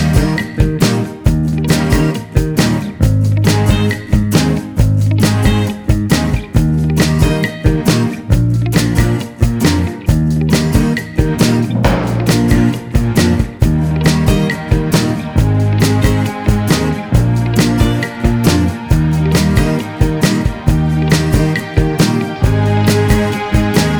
No Backing Vocals Rock 'n' Roll 2:25 Buy £1.50